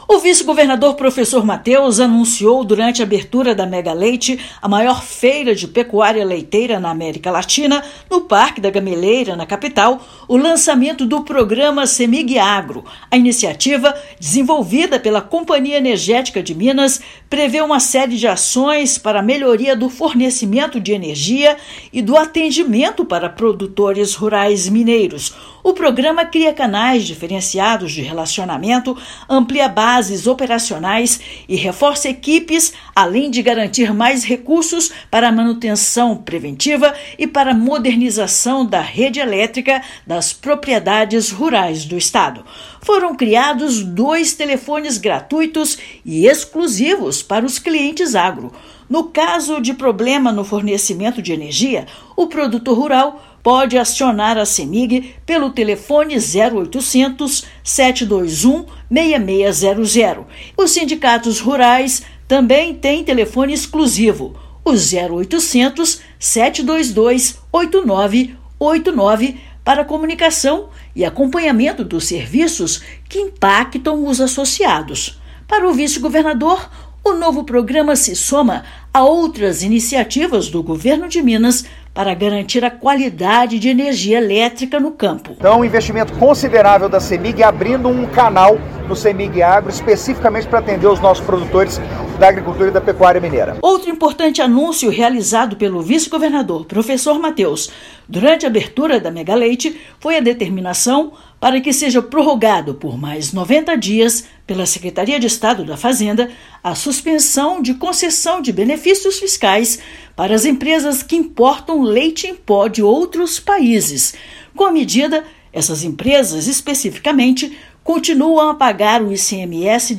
Agência Minas Gerais | [RÁDIO] Governo de Minas e Cemig lançam programa com série de medidas para beneficiar produtores rurais mineiros
Anúncio foi feito pelo vice-governador na abertura da 19ª Edição do Megaleite, maior exposição de pecuária leiteira da América Latina. Ouça a matéria de rádio: